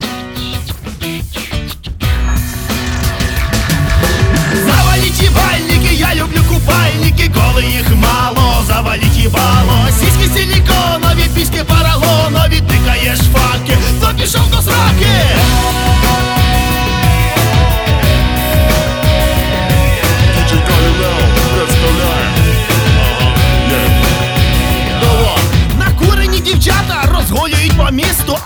• Качество: 320, Stereo
веселые
смешные
нецензурная лексика
с матом